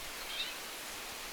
vähän erilainen järripeipon ääni
Olisiko nuoren järripeipon ääni.
tuollainen_vahan_erilainen_jarripeipon_aani.mp3